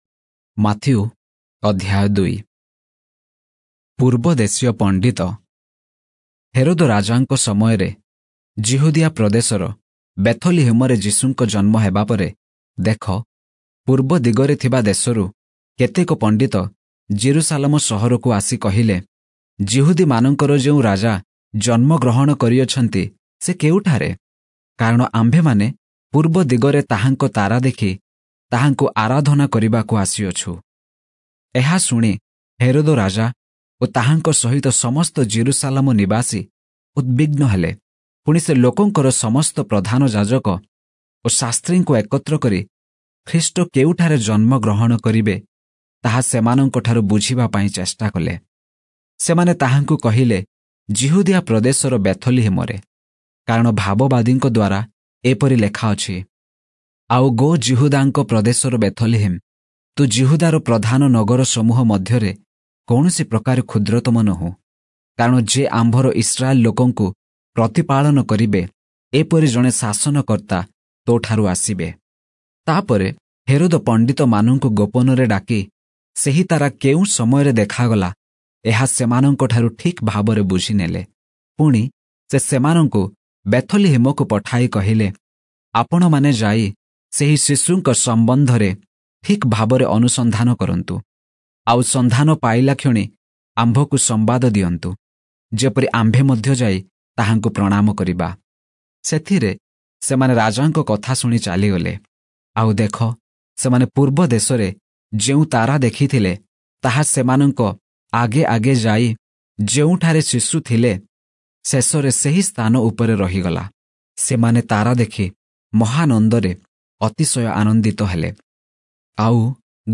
Oriya Audio Bible - Matthew 27 in Irvor bible version